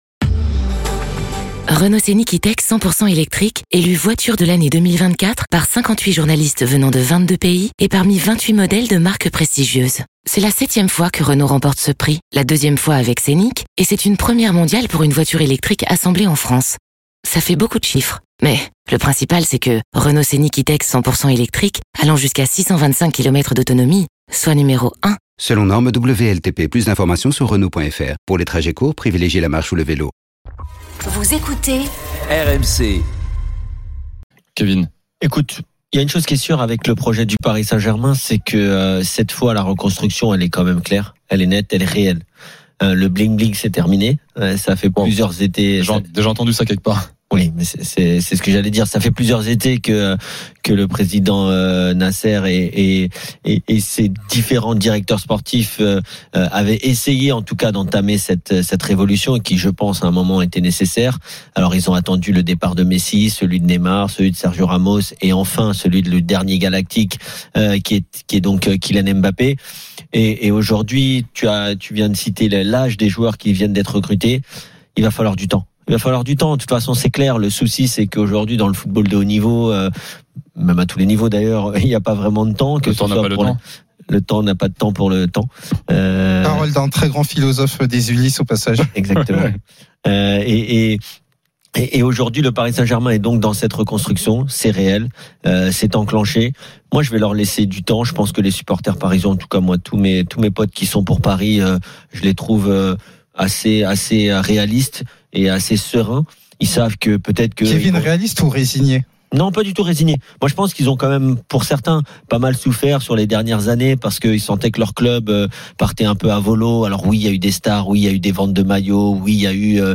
Chaque jour, écoutez le Best-of de l'Afterfoot, sur RMC la radio du Sport !